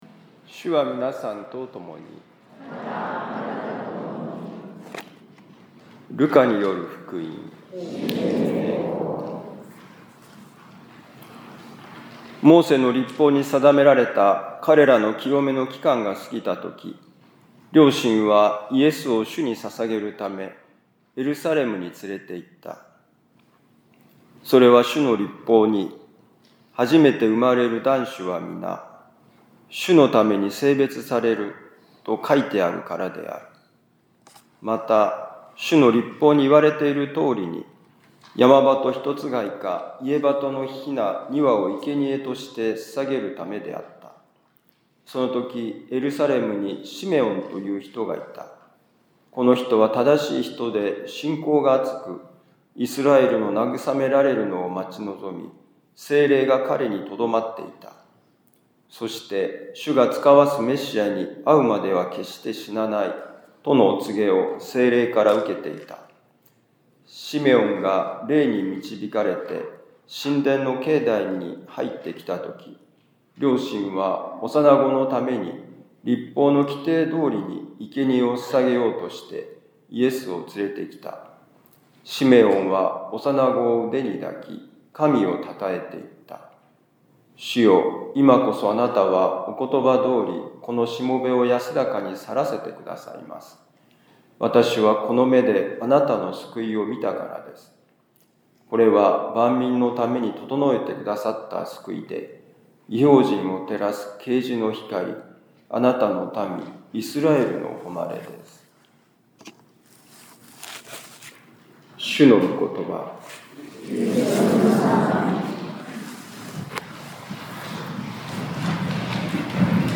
ルカ福音書2章22-40節「捧げる」2025年2月2日主の奉献のミサ六甲カトリック教会